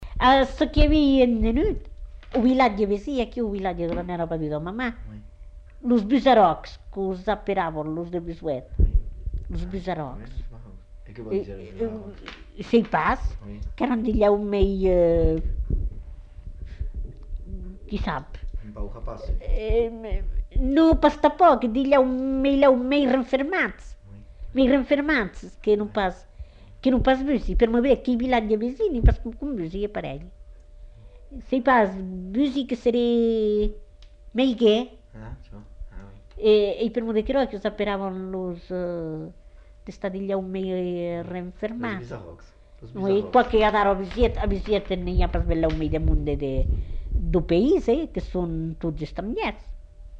Aire culturelle : Béarn
Genre : témoignage thématique